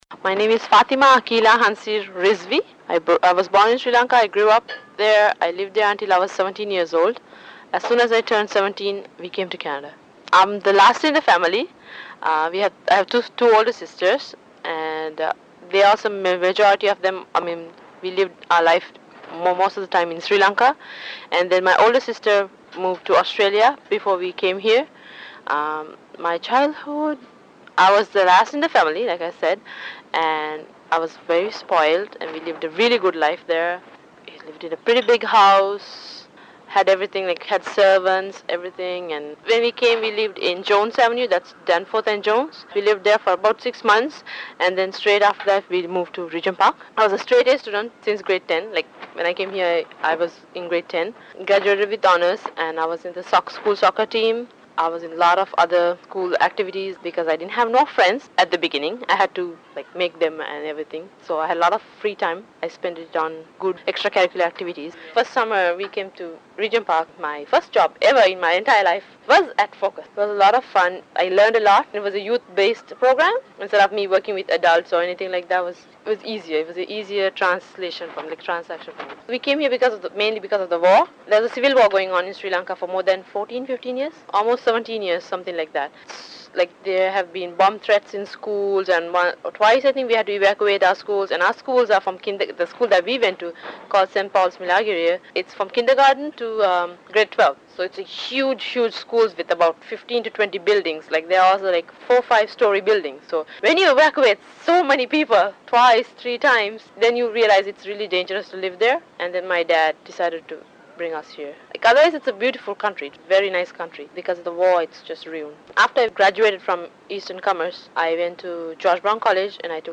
Journey Home is a story-telling project that explores the journey from homeland to Canada, through the voices of Regent Park residents.